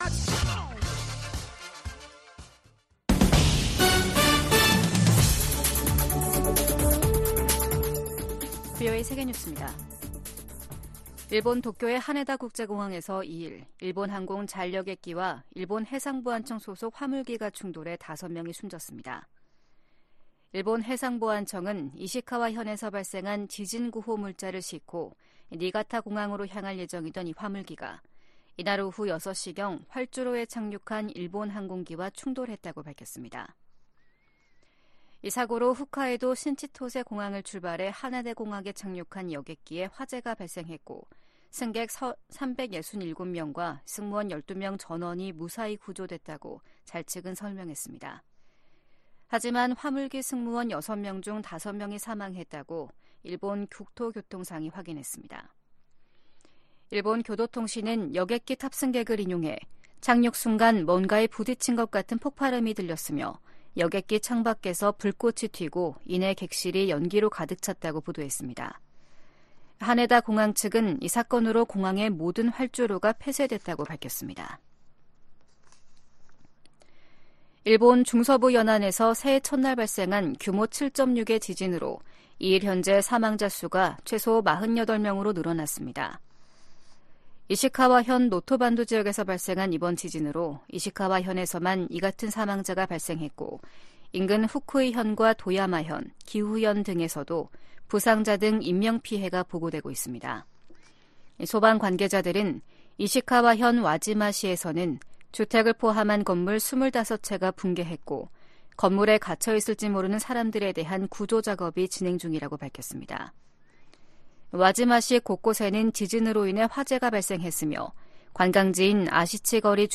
VOA 한국어 아침 뉴스 프로그램 '워싱턴 뉴스 광장' 2024년 1월 3일 방송입니다. 미 국무부가 김정은 북한 국무위원장의 추가 위성 발사 예고에 대륙간탄도미사일(ICBM) 개발과 다름없는 것이라는 입장을 밝혔습니다. 한국이 2년간의 유엔 안전보장이사회 비상임이사국 활동을 시작했습니다. 북한과 러시아가 미국의 금융 제재망을 우회해 무기 거래를 지속할 우려가 있다고 미국 전문가들이 지적했습니다.